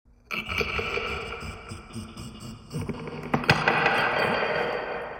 A segment of "Glass Break 1" audio, slowed down slightly, with an added spatial audio effect. This sound is correlated with the letter "h" on the computer keyboard.